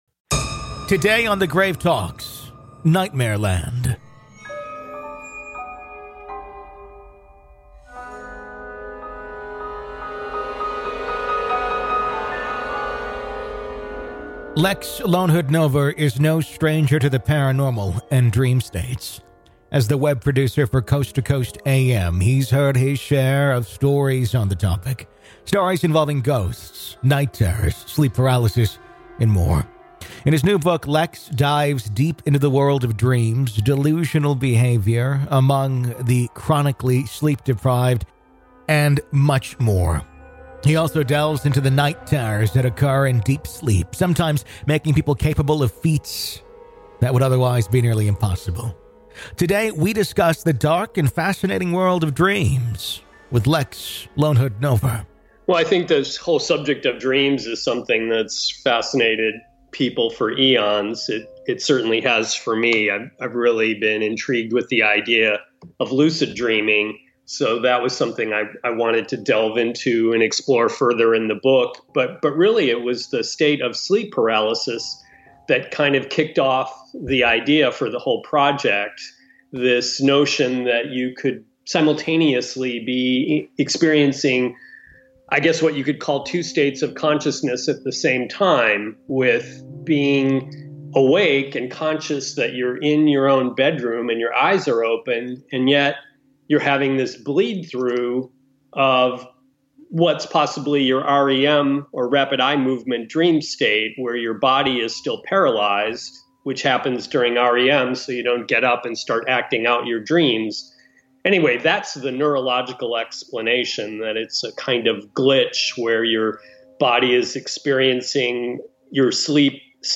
Today, in Part One of our conversation